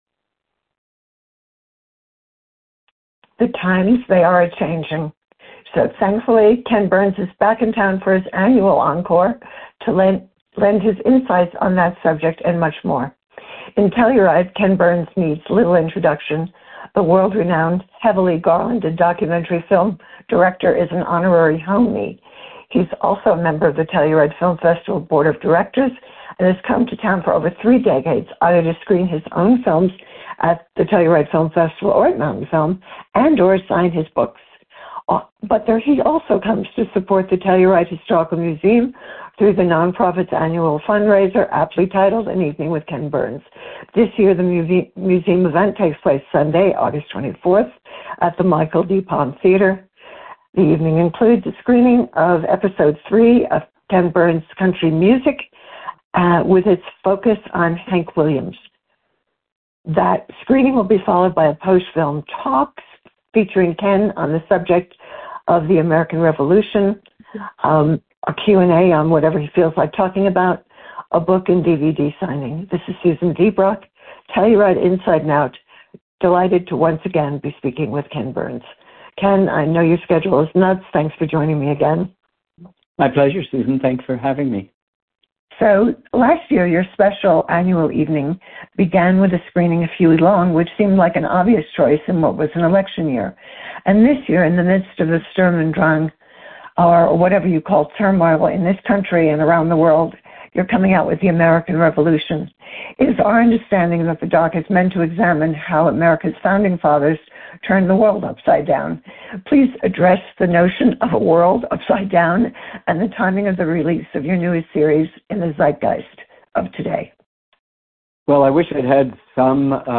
Please scroll down to listen to TIO’s most recent podcast with Ken Burns.